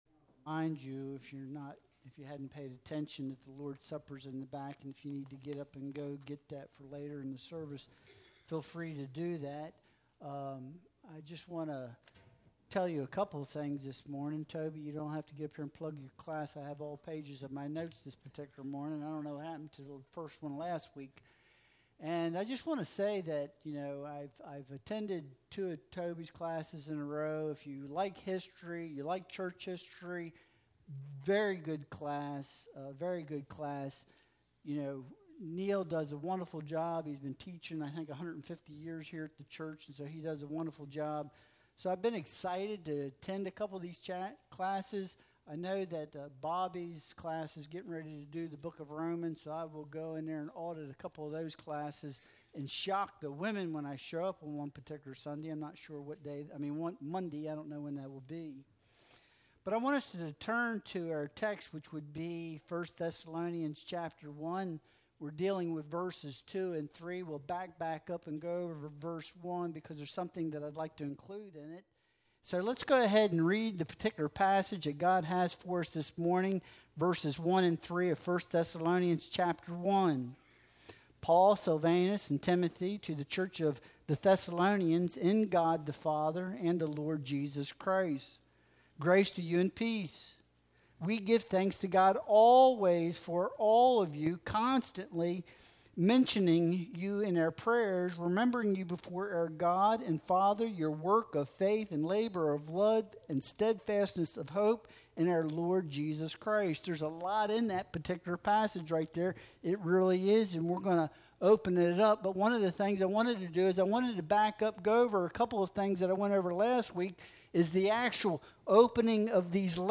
1 Thessalonians 1.2-3 Service Type: Sunday Worship Service Download Files Bulletin « Election